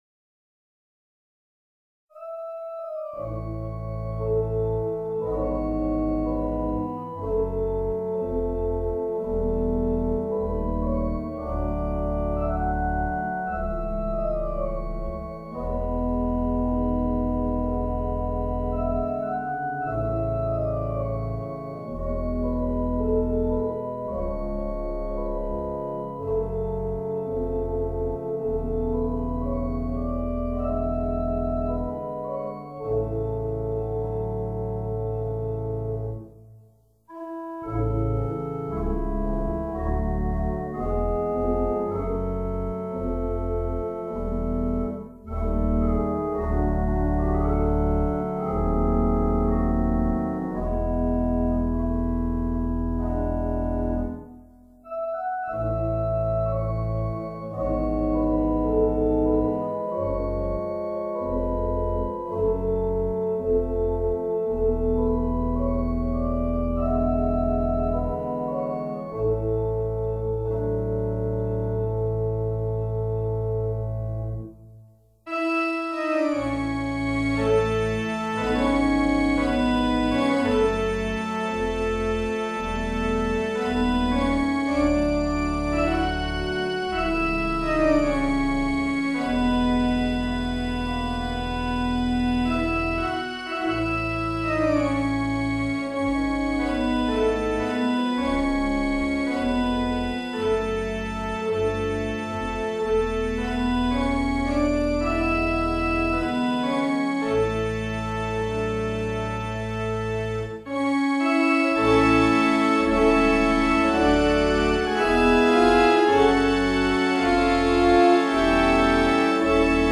Virtual Theatre Pipe Organ